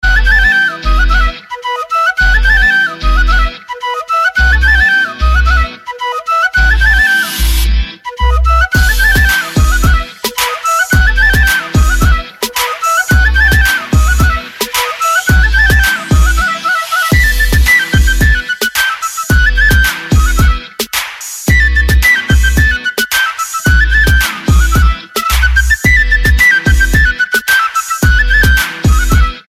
• Качество: 320, Stereo
позитивные
без слов
Крутая игра на флейте